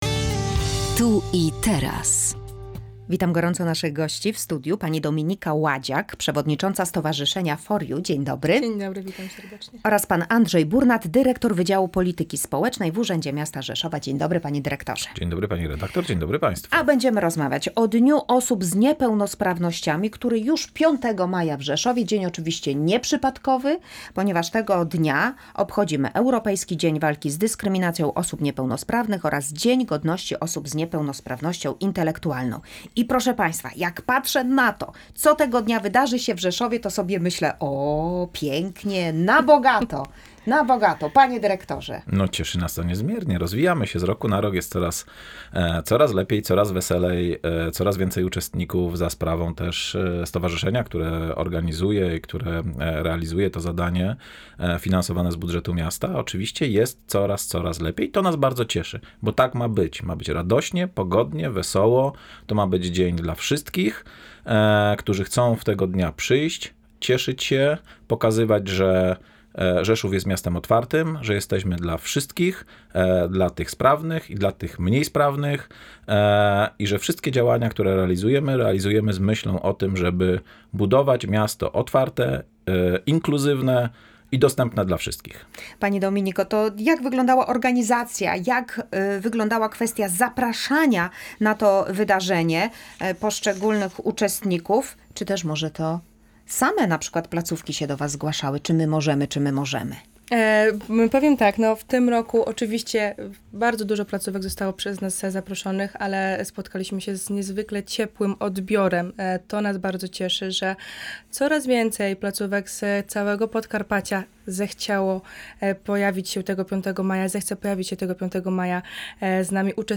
W audycji "Tu i Teraz" rozmawialiśmy o zbliżającym się Dniu Osób z Niepełnosprawnościami w Rzeszowie, który odbędzie się już 5